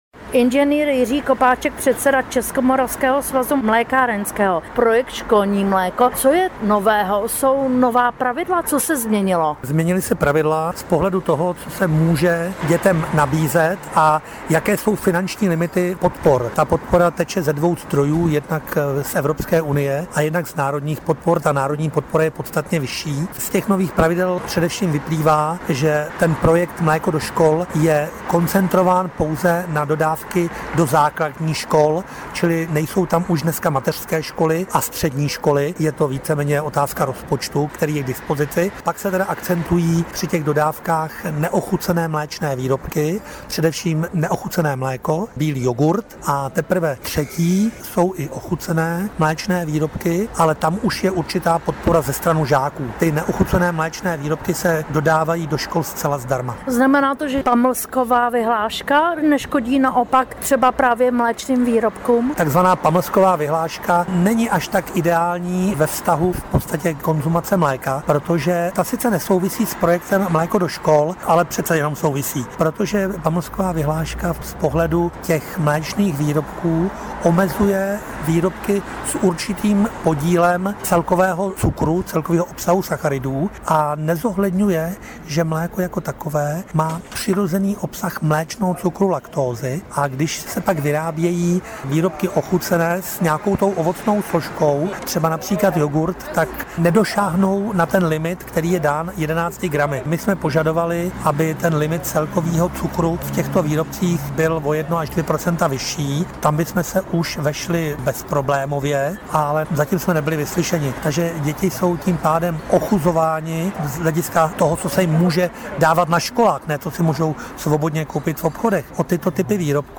Rozhovor s odborníky o projektu „Mléko do škol“.